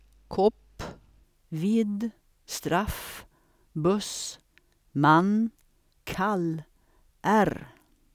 7.7: svensk [kʰop: vid: straf: bɵs: man: kʰal: ɛr:]